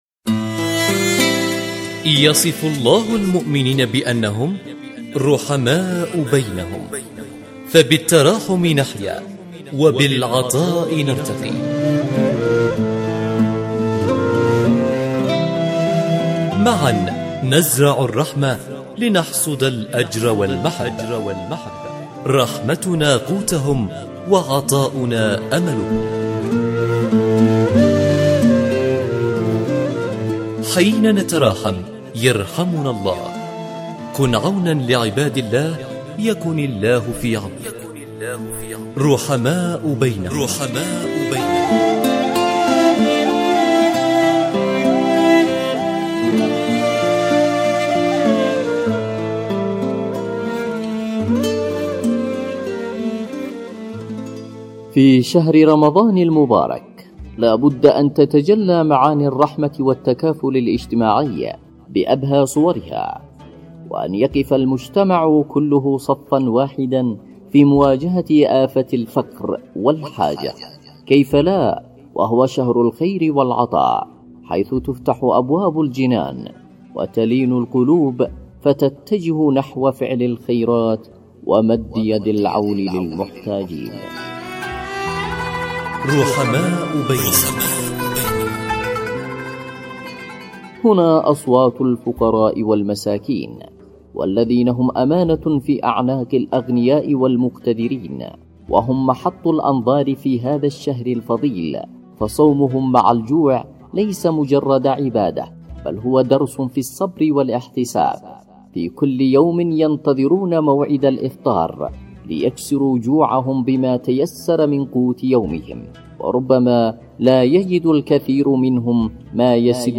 رحماء بينهم، برنامج إذاعي يأخذكم في رحلة إذاعية قصيرة ، نستكشف خلالها العديد من الحالات الإنسانية التي تحتاج الى مد يد العون في شهر الخيرات، ويسلط الضوء على حالات إنسانية.